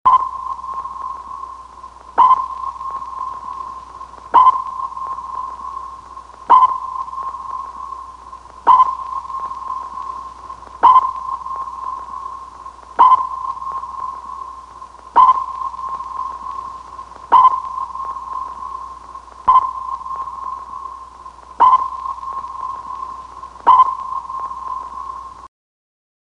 Suoneria Sottomarino Sonar Ping
Categoria Effetti Sonori